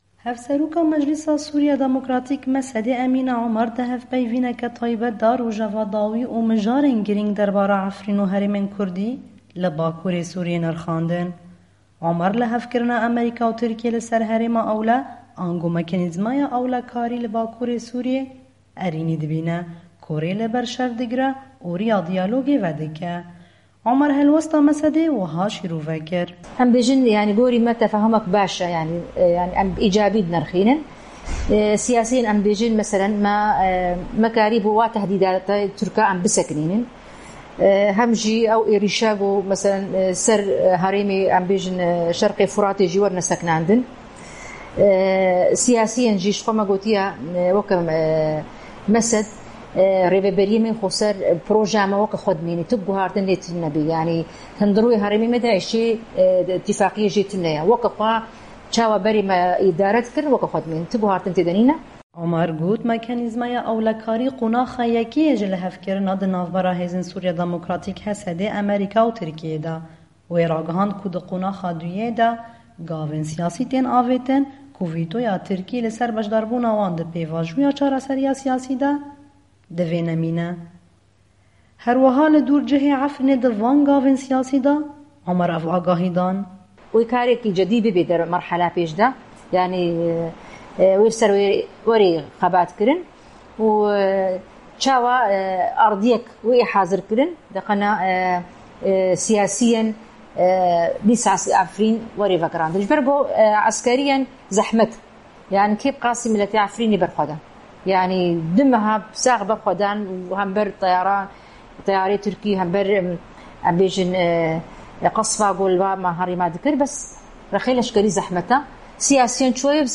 Hevseroka Meclîsa Sûrîya Demokratîk (MSD) Emîne Omer di hevpeyvîneke taybet de rojeva dawî û mijarên girîng derbara Efrîn û herêmên Kurdî li Bakurê Sûrîyê nirxandin.
Raporta Deng